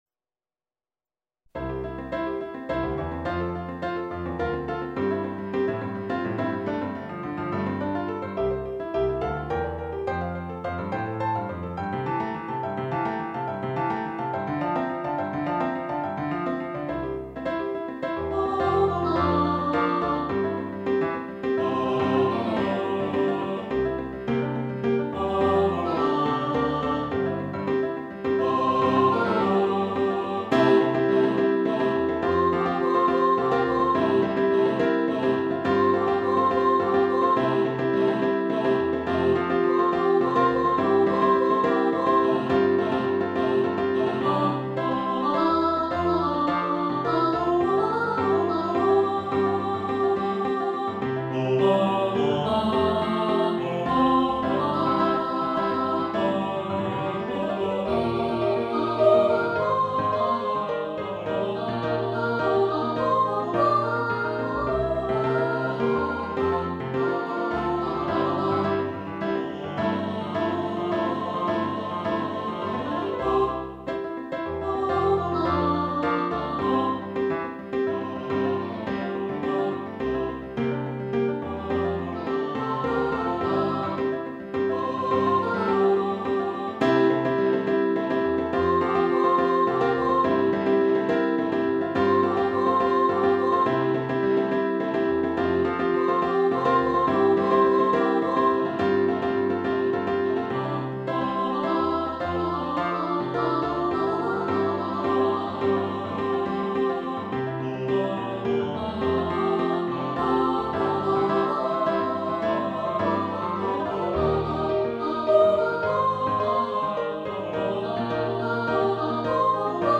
soprano, baritone, choir, piano
Electronically Generated